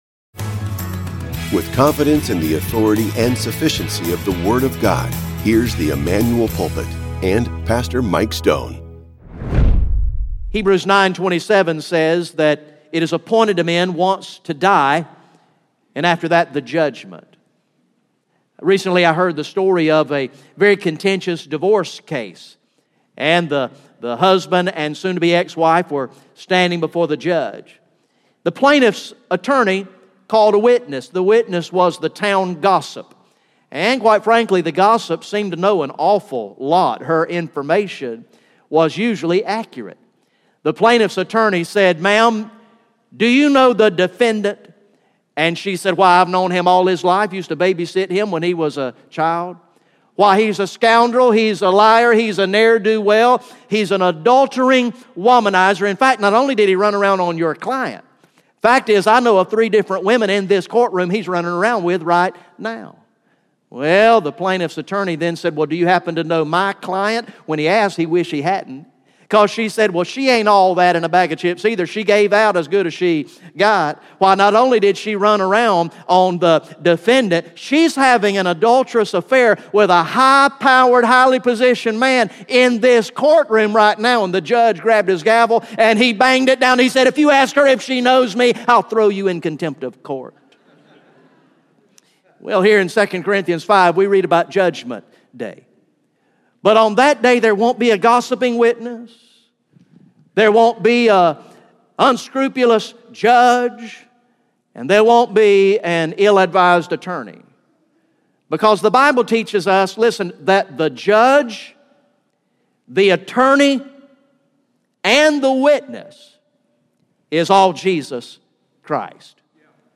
GA Sunday AM